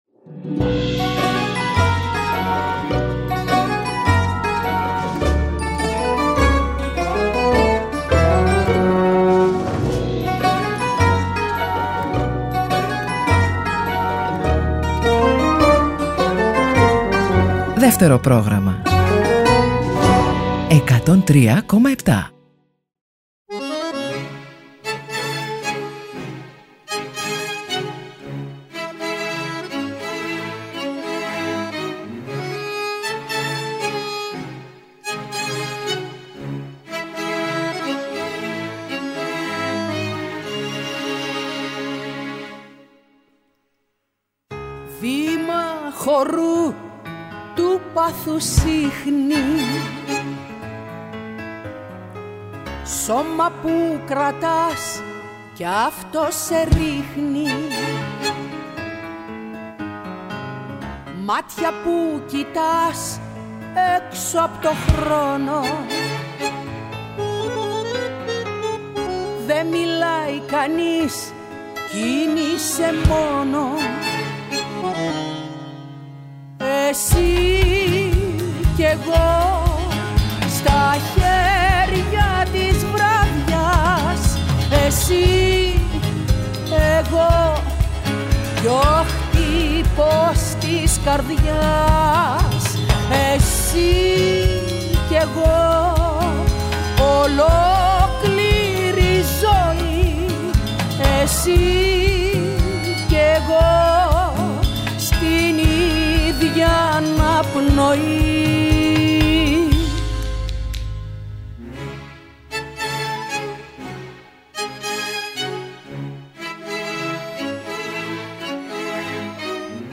Τί καλύτερο για το Σαββατόβραδο από μια εκπομπή με τραγούδια που αγαπήσαμε;